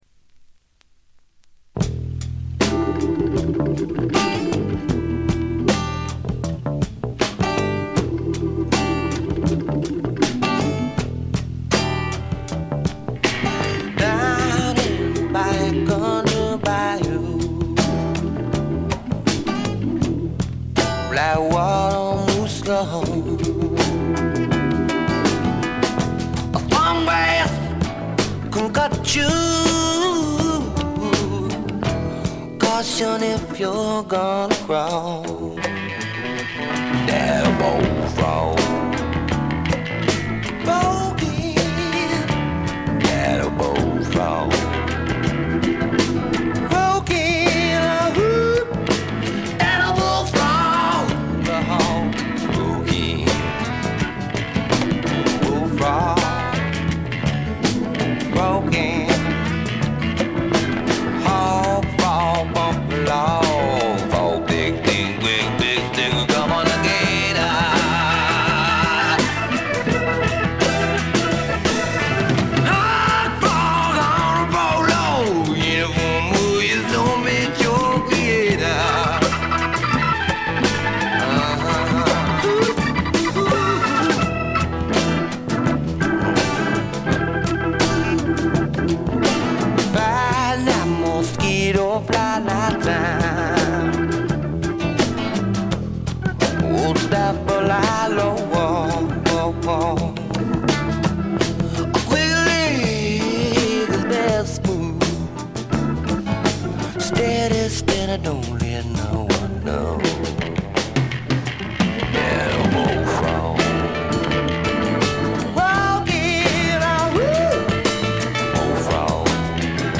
部分試聴ですが、軽微なノイズ感のみ。
試聴曲は現品からの取り込み音源です。